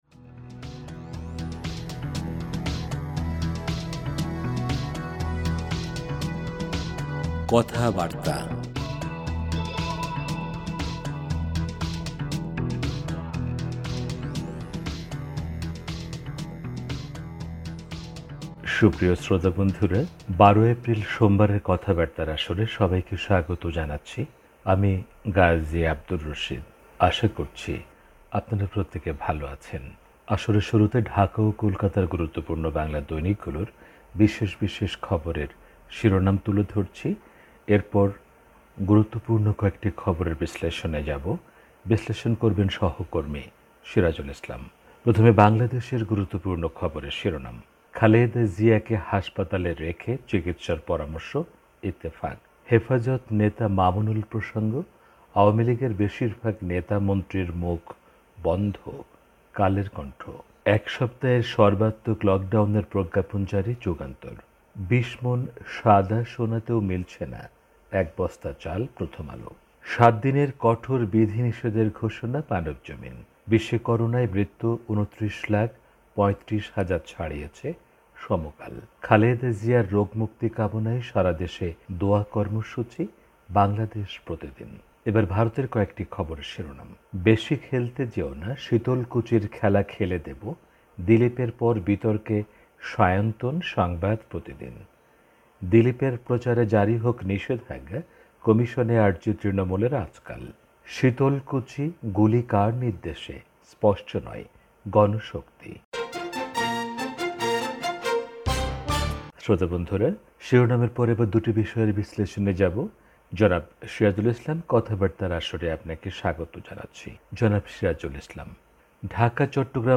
আসরের শুরুতে ঢাকা ও কোলকাতার গুরুত্বপূর্ণ বাংলা দৈনিকগুলোর বিশেষ বিশেষ খবরের শিরোনাম তুলে ধরছি। এরপর গুরুত্বপূর্ণ কয়েকটি খবরের বিশ্লেষণে যাবো।